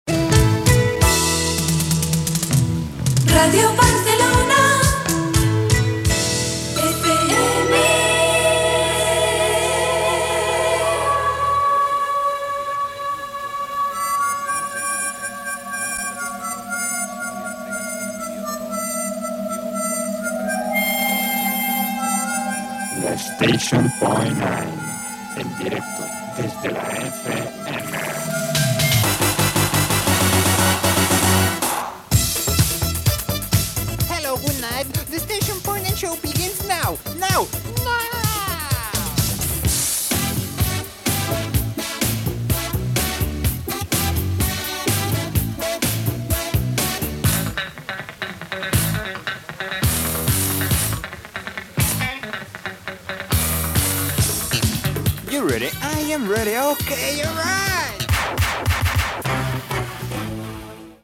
Indicatiu de l'emissora i careta del programa